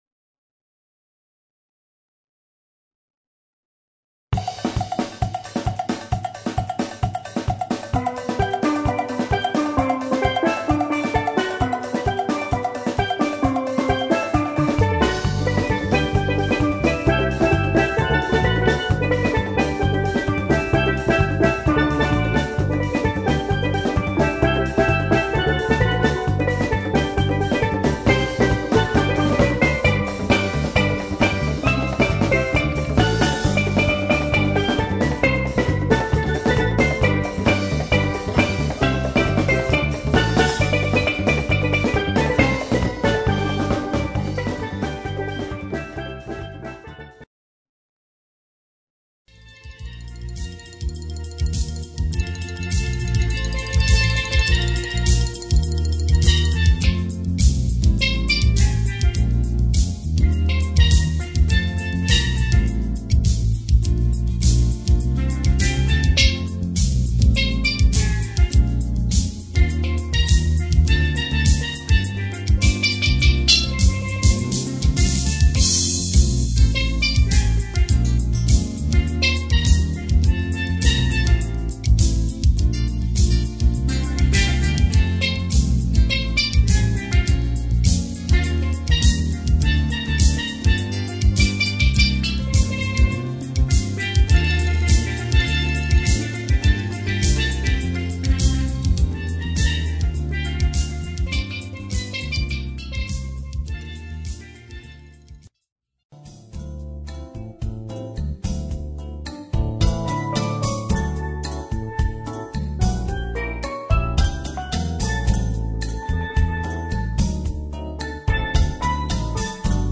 Panorama is the Caribbean steel pan Calypso / Soca / Tropikal Jazz group in Texas and features 1 to 5 steel pans, plus a hot, international rhythm section and the "Skankin' Horns" to kick up the "carnival" with a full 10 piece set of great players from Trinidad, St. Croix, St. Kitts, Jamaica, Grenada, Brazil and the USA.